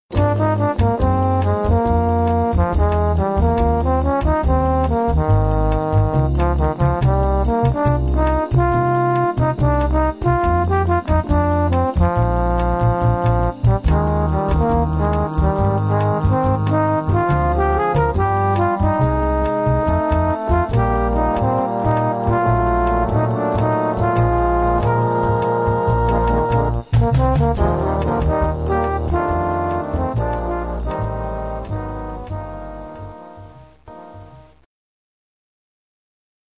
- bossa nova